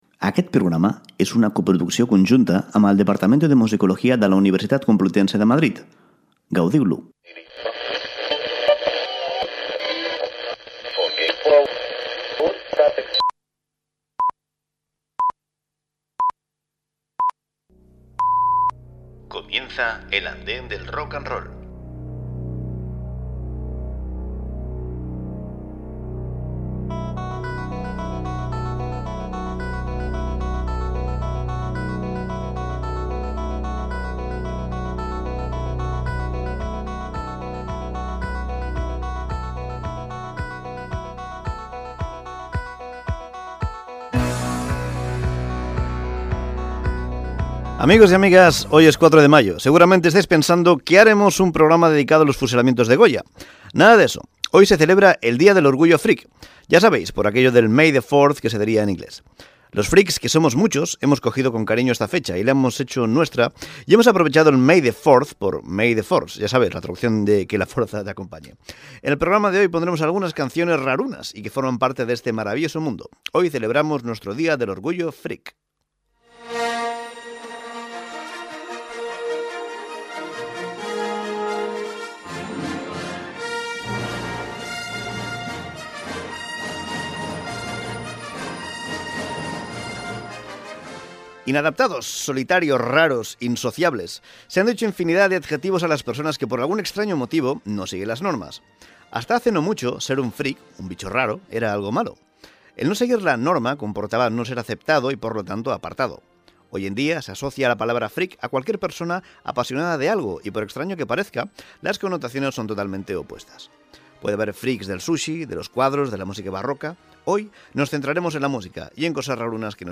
L'andana de l'Rock and Roll és un programa musical setmanal dedicat a repassar el millor de la música moderna.